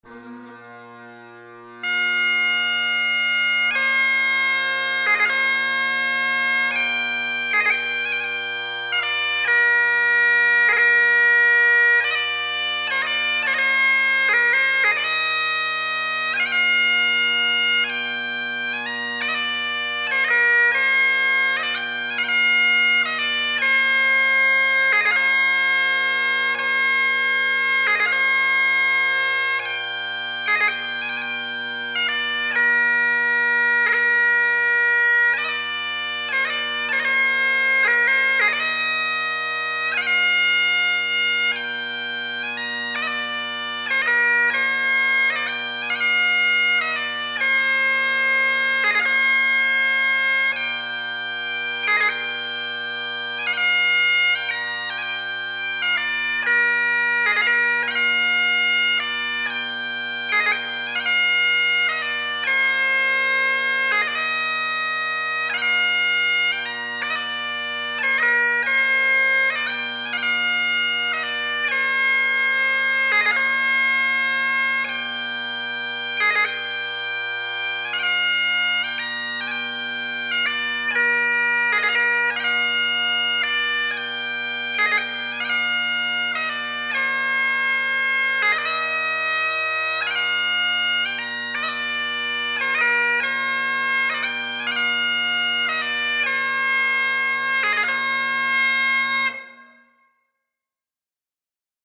Scottish and Irish Bagpipe Music
Mist Covered Mountains – Slow Air